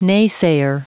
Prononciation du mot naysayer en anglais (fichier audio)
Prononciation du mot : naysayer